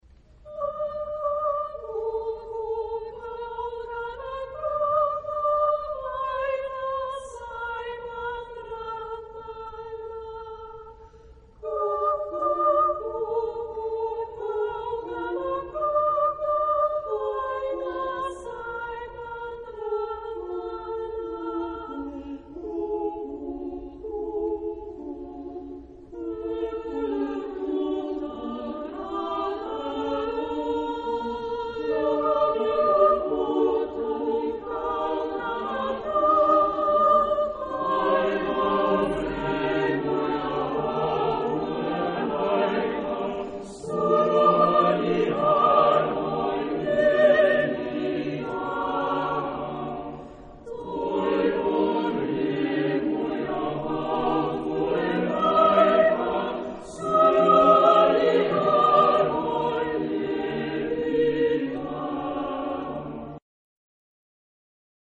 Genre-Style-Forme : contemporain ; Profane ; Populaire
Caractère de la pièce : mélancolique ; lié ; adagio ; doux
Type de choeur : SATB  (4 voix mixtes )
Tonalité : polymodal